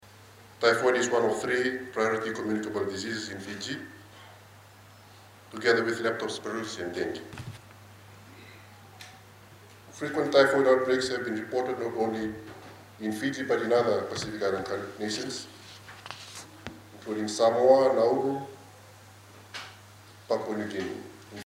Speaking at the launch, Minister for Health and Medical Services Doctor Atonio Lalabalavu says the campaign is a milestone for the ministry and its partners.